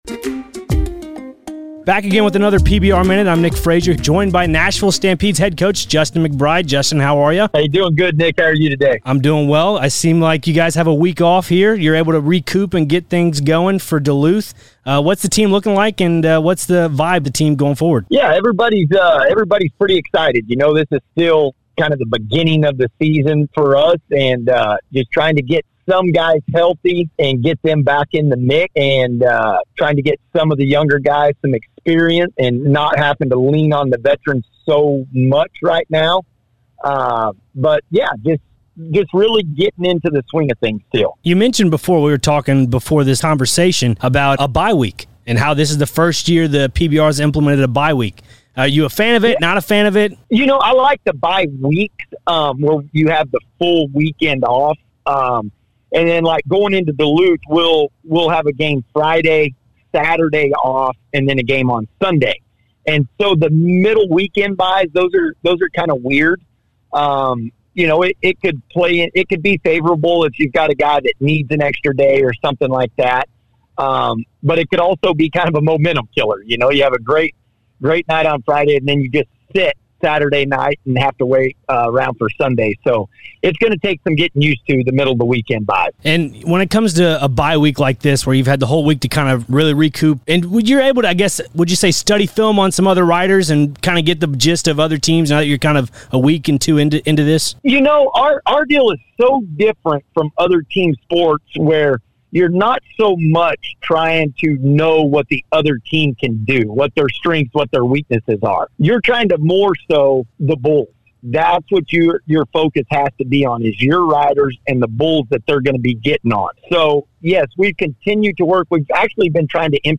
chatted with Nashville Stampede Head Coach Justin McBride ahead of week two in Duluth Georgia. Justin talked about the vibe of his team going into their upcoming rides this weekend.